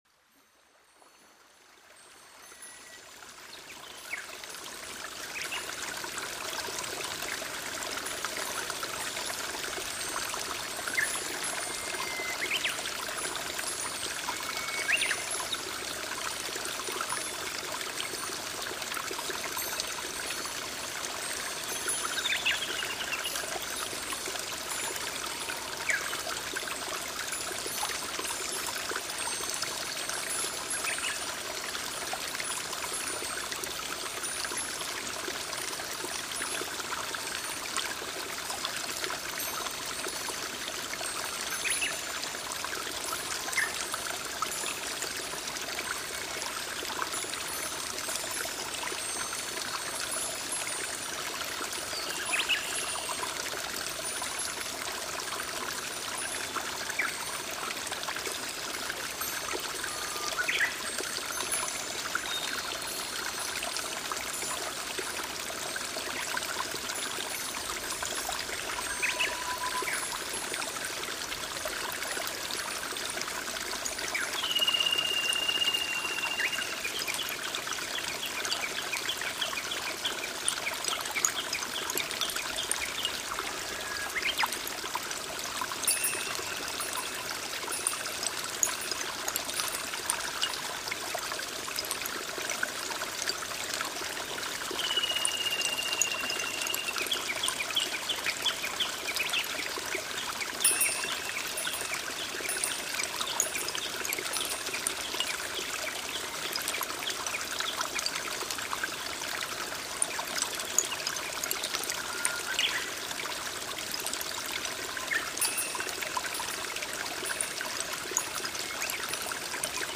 居家Home SPA音乐
新世纪身^心^灵美丽养成音乐秘籍